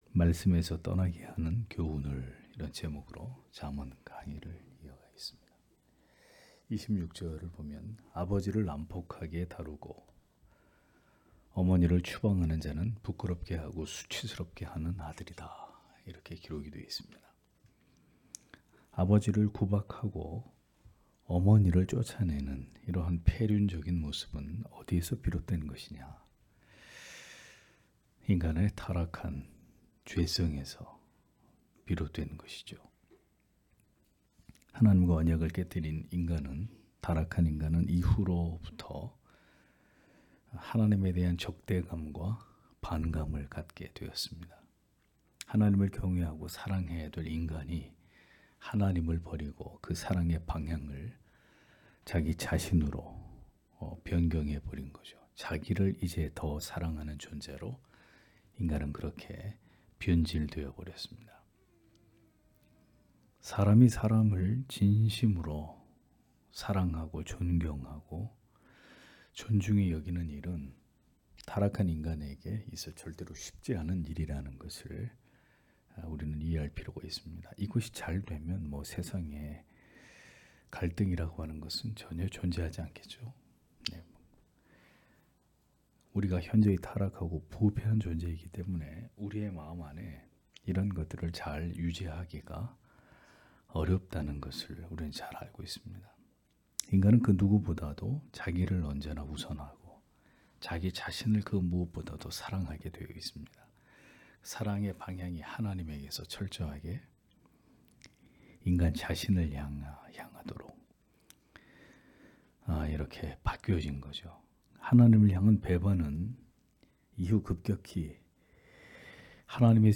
수요기도회 - [잠언 강해 120] 말씀에서 떠나게하는 교훈을 (잠 19장 26-27절)